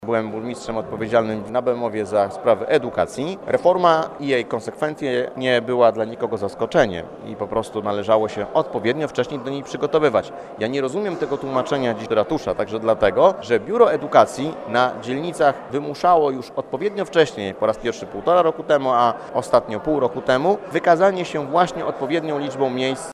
– Reforma oświaty przy odpowiednim zarządzaniu nie jest problemem – zaznacza radny PiS Błażej Poboży.